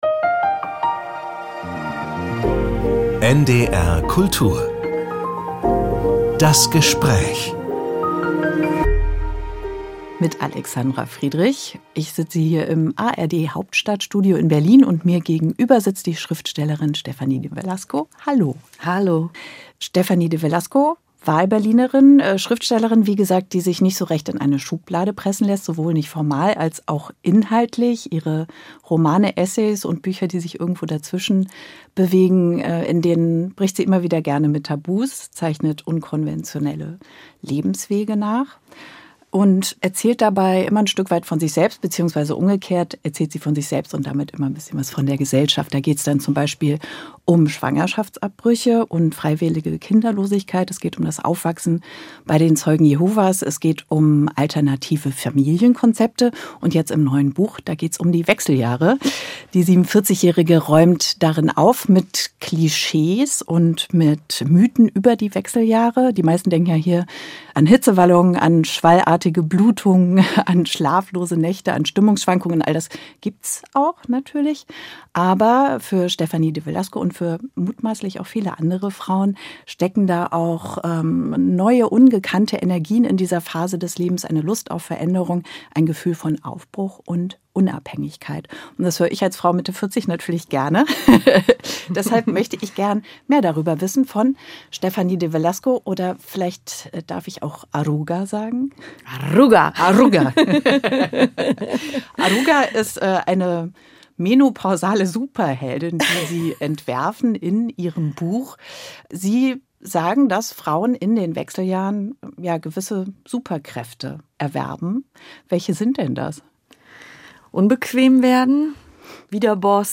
Wechseljahre - Srefanie de Velasco über Menopause und Superpower ~ NDR Kultur - Das Gespräch Podcast